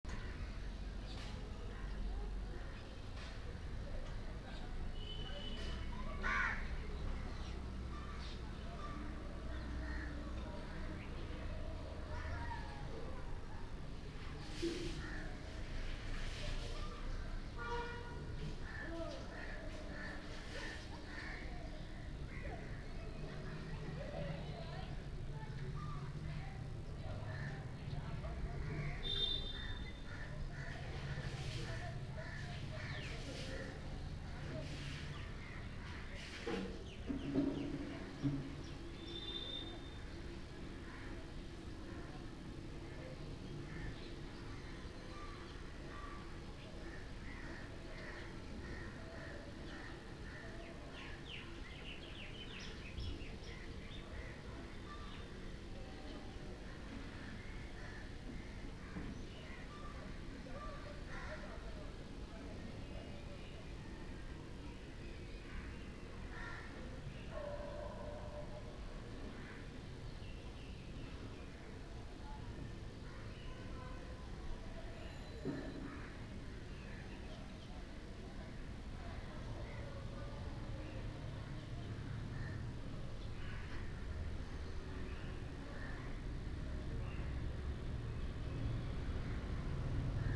Des mondes au creux de l’oreille est une promenade sonore autour du Vieux-Port de Marseille qui vous connecte, au fil de vos pas, à des environnements sonores naturels captés en direct autour du monde.
Kolkata.m4a